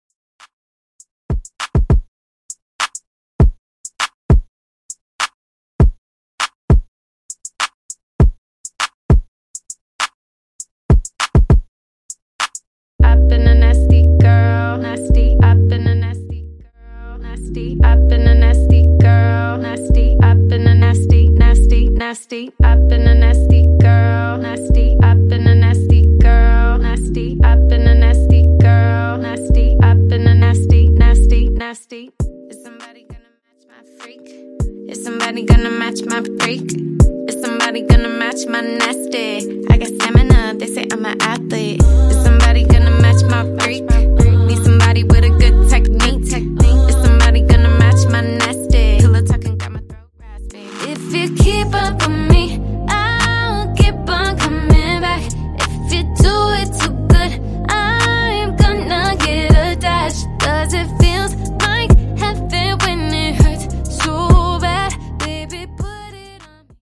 Genre: TOP40
Clean BPM: 90 Time